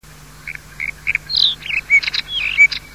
głosy innych białorzytek